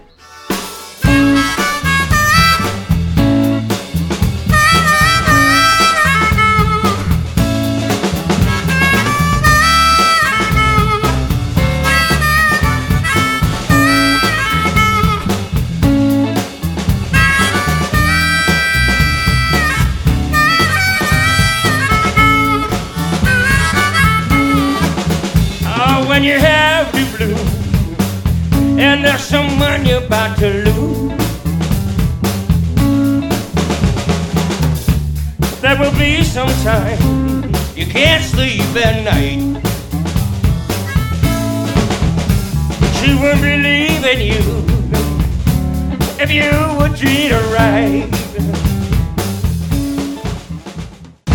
It's a fast paced show